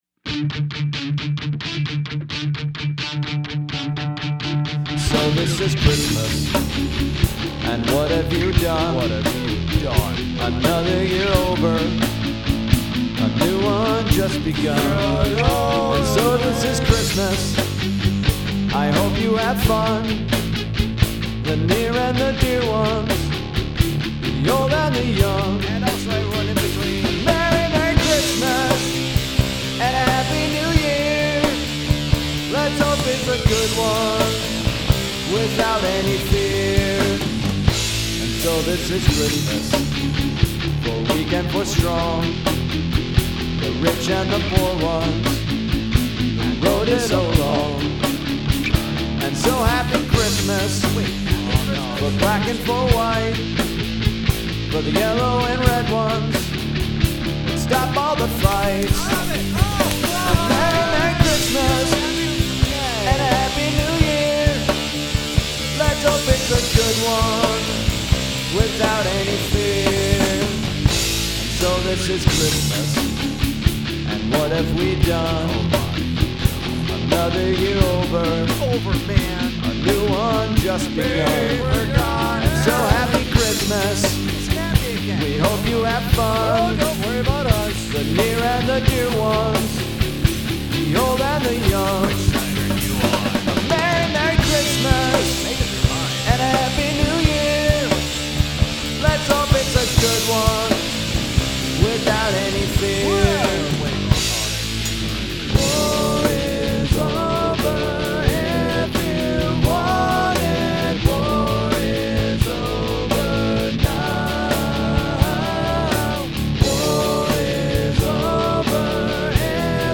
Fighting